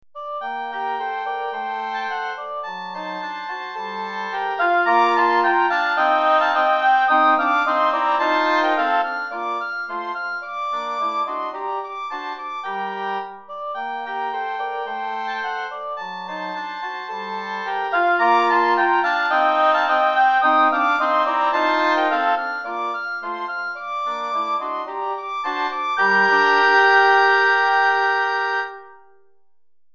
3 Hautbois et Cor Anglais